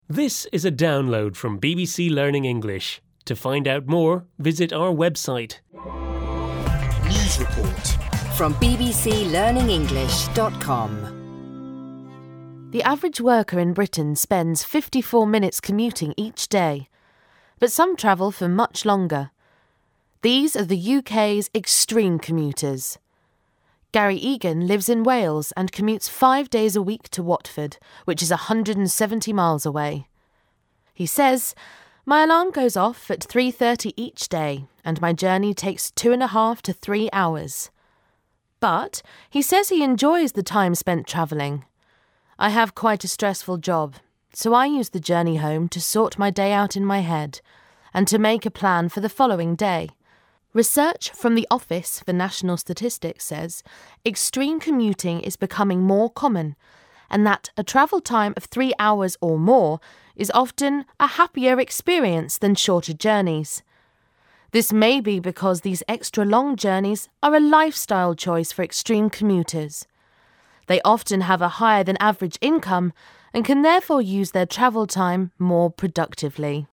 unit-4-3-1-u4_s4_newsreport_commuting_download.mp3